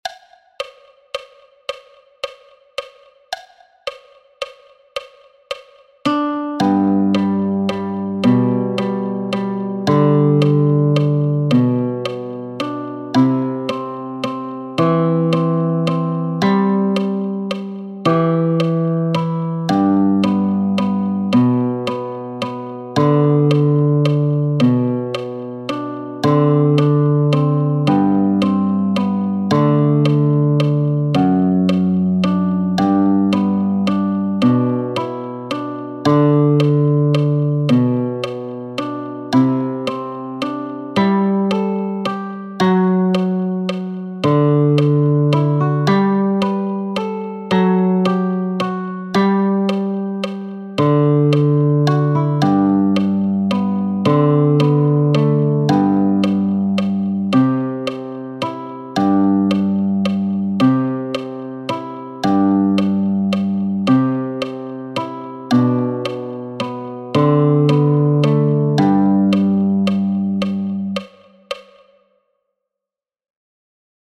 für die Gitarre